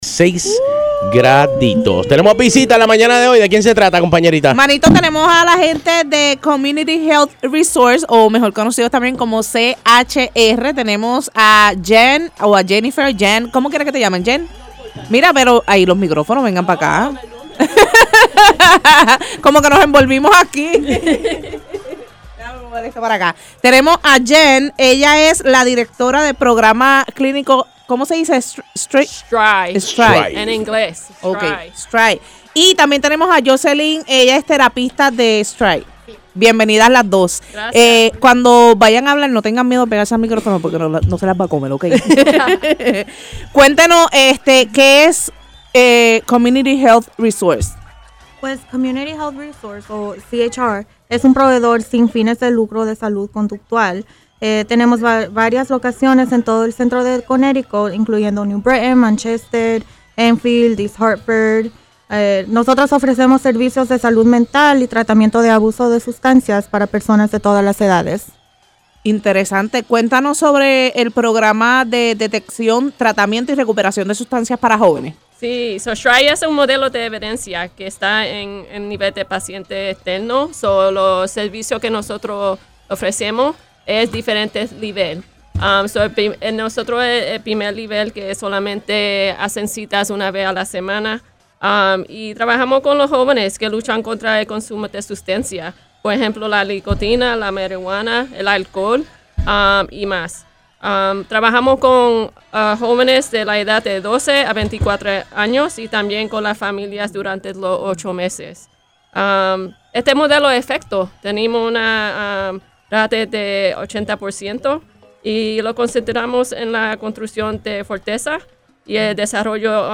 CHR-INTERVIEW-DEC-20.mp3